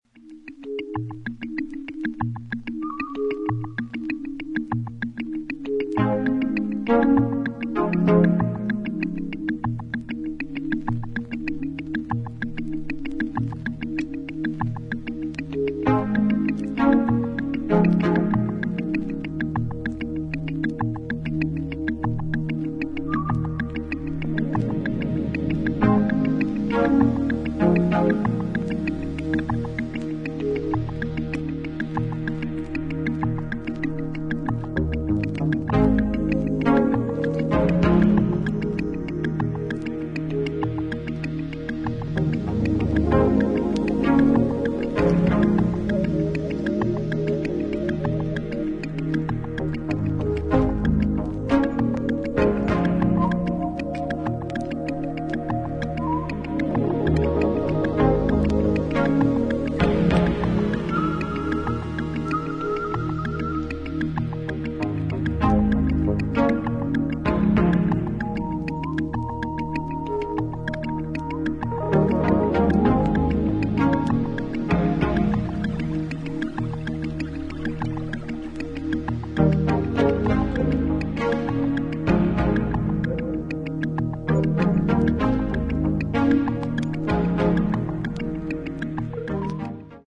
バレアリックなムードの中に幻想的な雰囲気が漂う